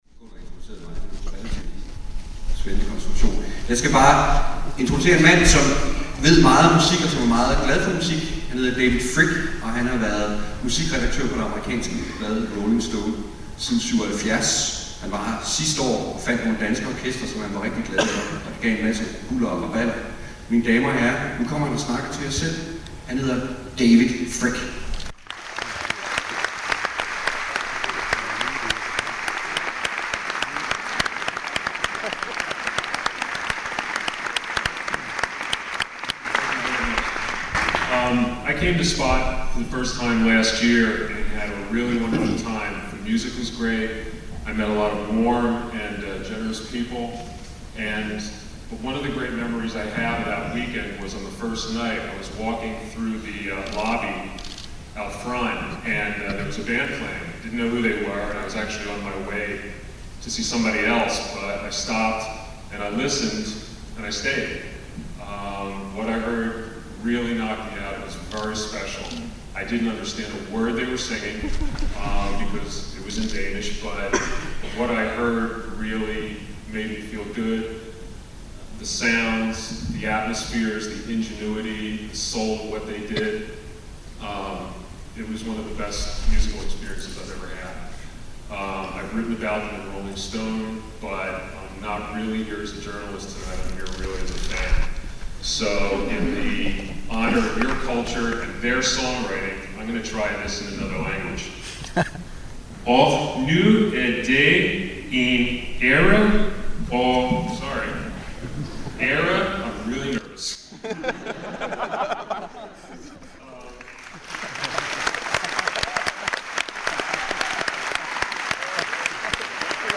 Under_Byen_Spot_Festival_2003_David_Fricke_Introduction.mp3